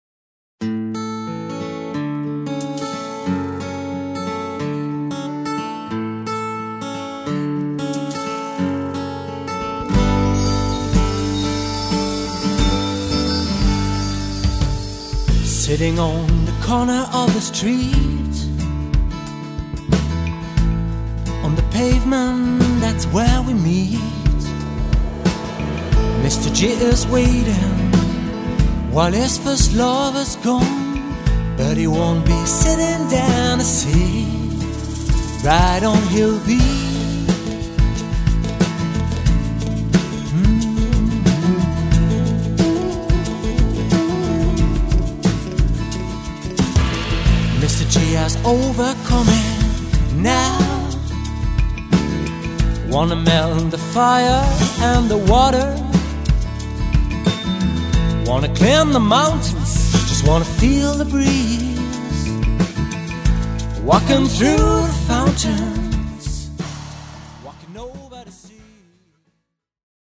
Genre : Pop Folk